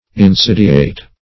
Search Result for " insidiate" : The Collaborative International Dictionary of English v.0.48: Insidiate \In*sid"i*ate\, v. t. [L. insidiatus, p. p. of insidiare to lie in ambush, fr. insidiae.